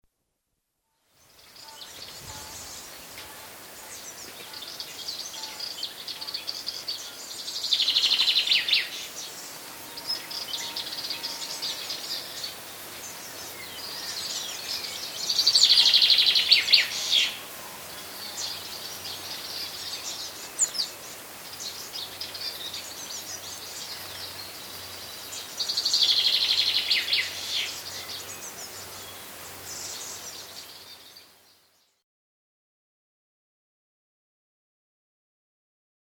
birds.mp3